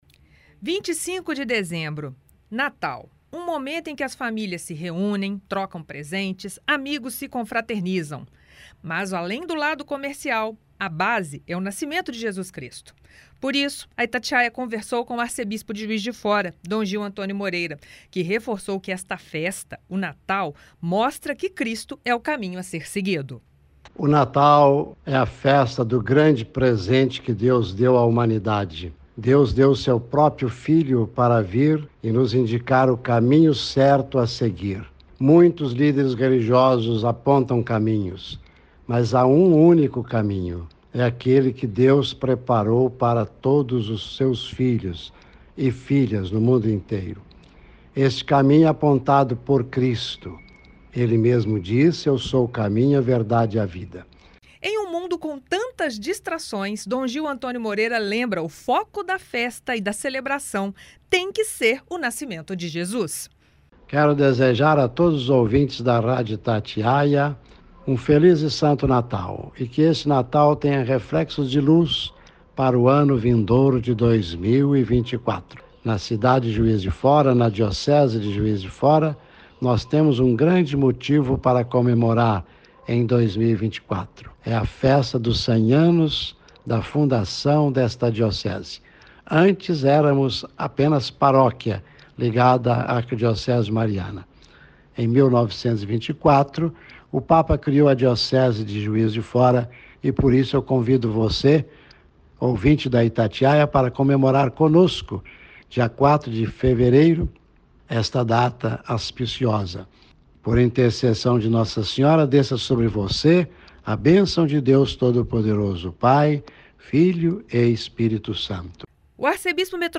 A Itatiaia conversou com o Arcebispo Metropolitano de Juiz de Fora, Dom Gil Antônio Moreira, sobre o verdadeiro sentido da celebração, em um mundo repleto de distrações.